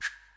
window-slide.ogg